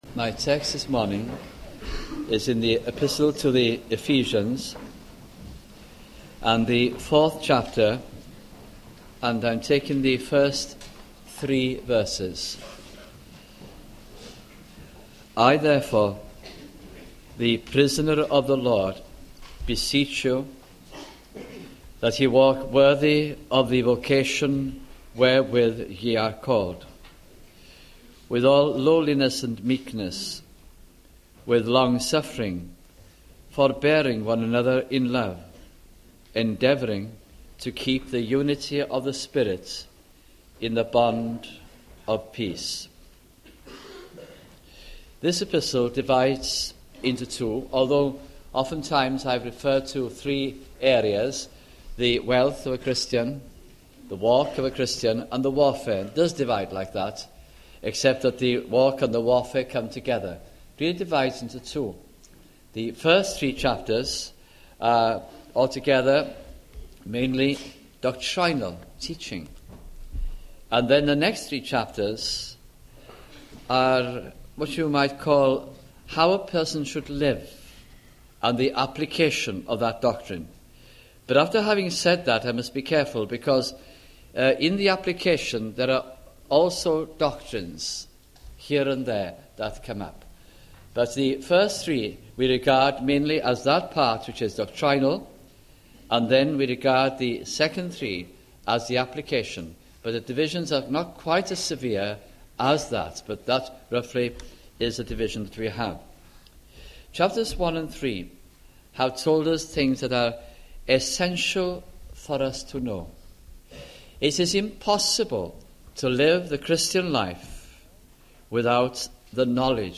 » Ephesians Series 1991 » sunday morning messages